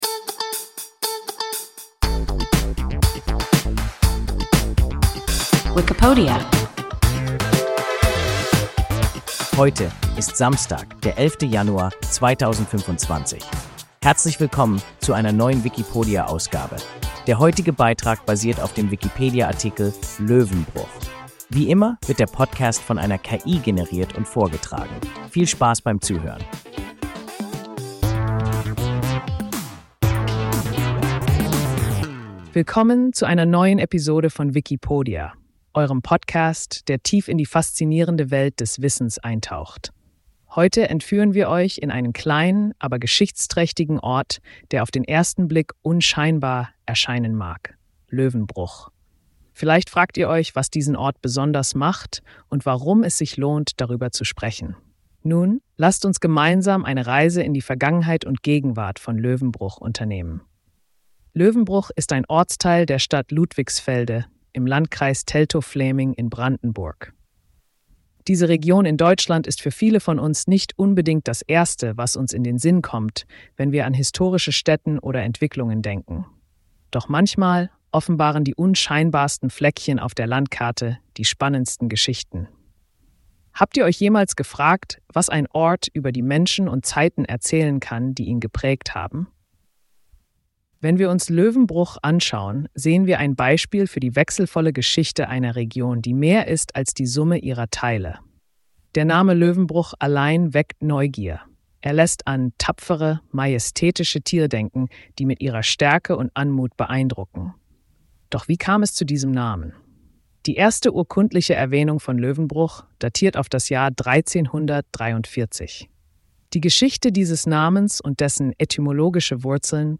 Löwenbruch – WIKIPODIA – ein KI Podcast